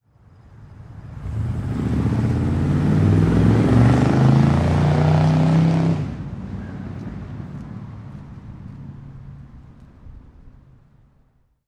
随机的 " 汽车性能汽车拉走快速起飞大的连续转速
描述：汽车性能车拉开快速起飞大连续转速
Tag: 性能车 速度快 汽车